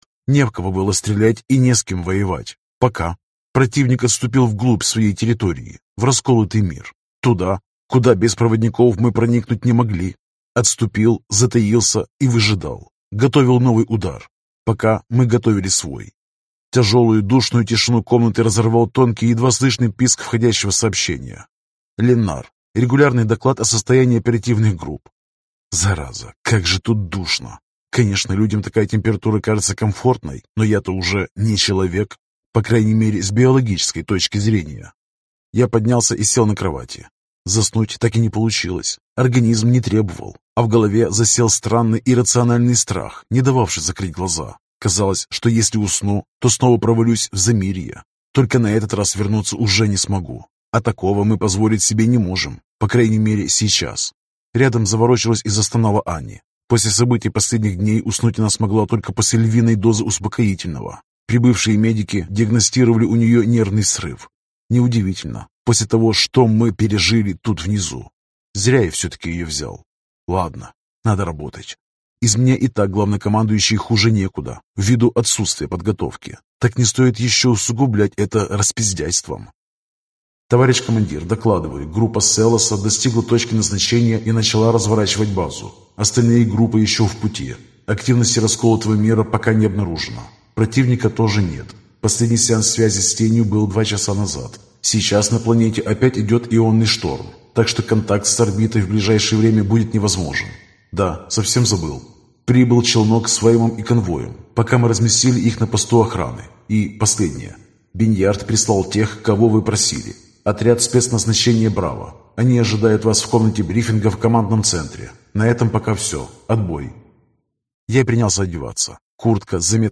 Аудиокнига Тени Деймона. Операция «Возвращение». Том 2 | Библиотека аудиокниг